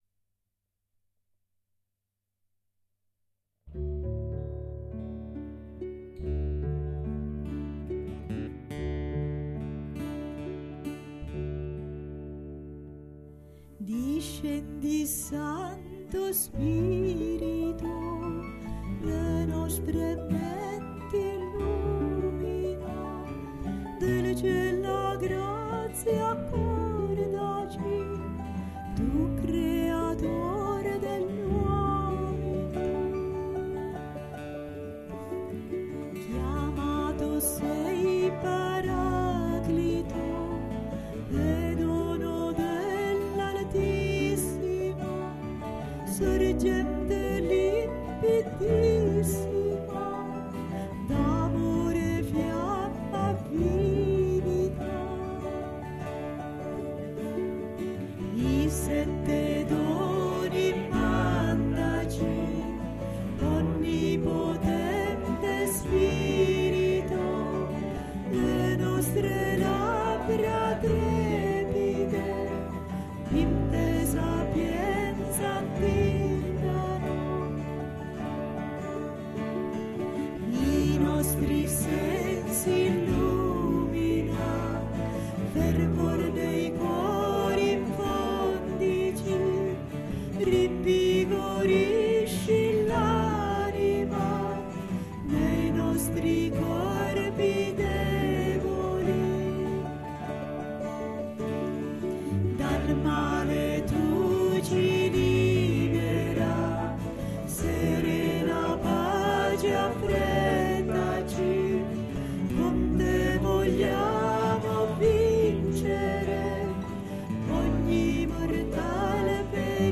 Discendi Santo Spirito canto mp3